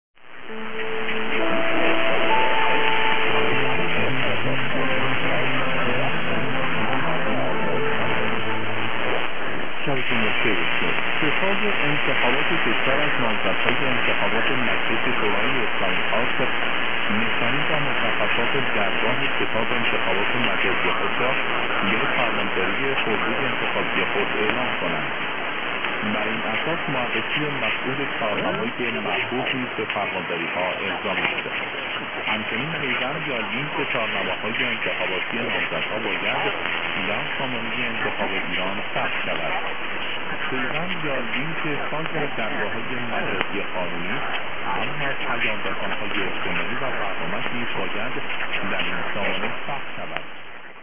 listen to the Radio Payam tune from Feb 2024.